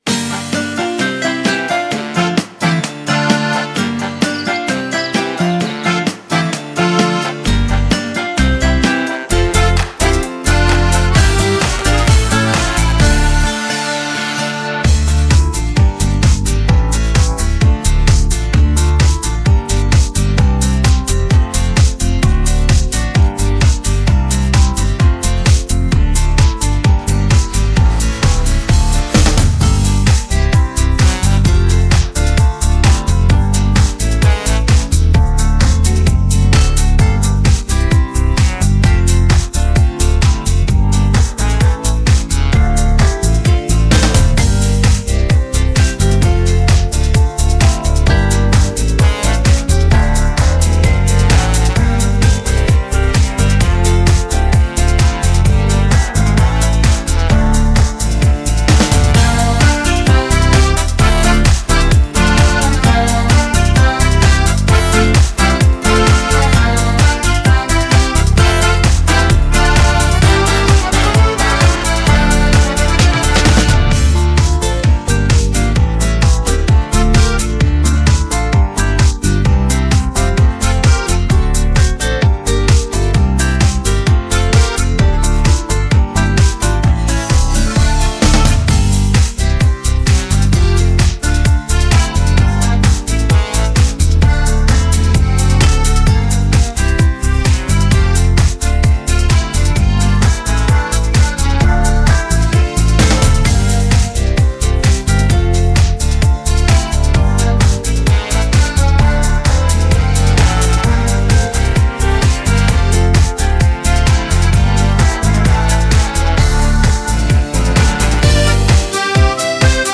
• Категория: Детские песни
| караоке
Скачать минус детской песни